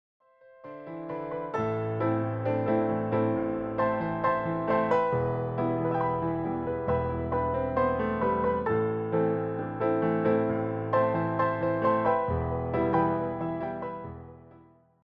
clear and expressive piano arrangements